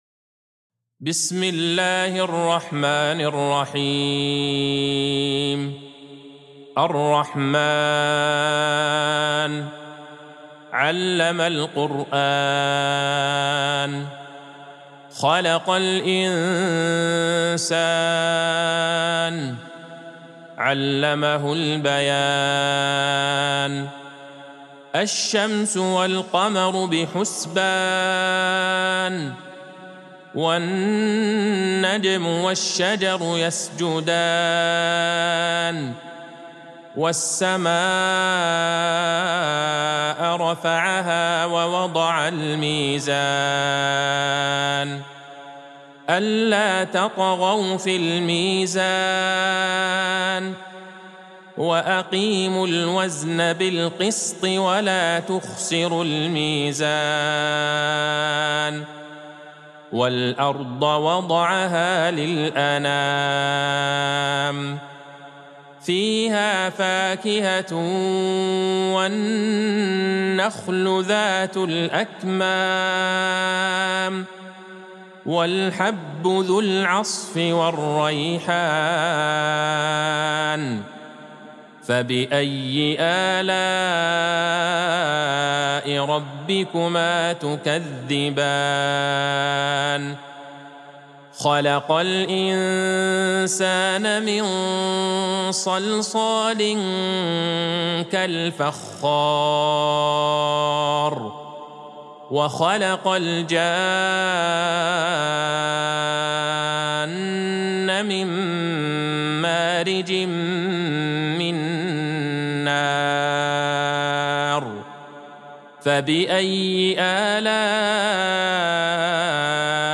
سورة الرحمن Surat Ar-Rahman | مصحف المقارئ القرآنية > الختمة المرتلة